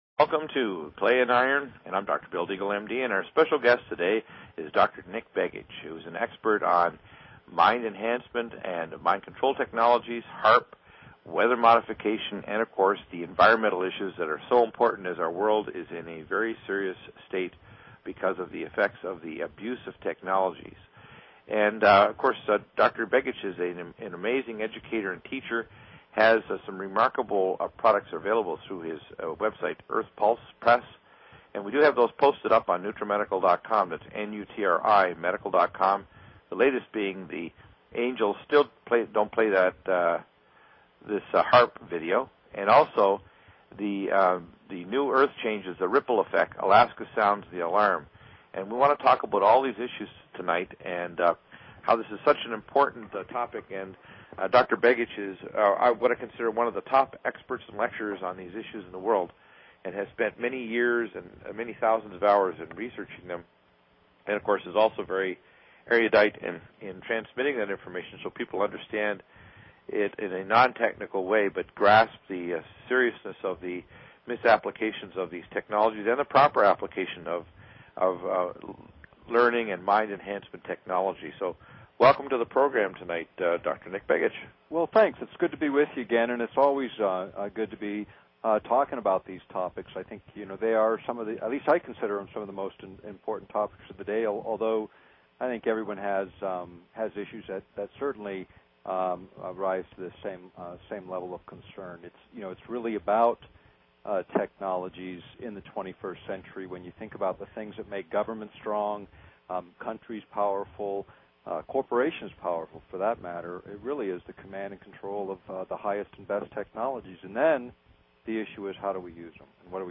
Talk Show Episode, Audio Podcast, Clay_Iron and Courtesy of BBS Radio on , show guests , about , categorized as
Interview w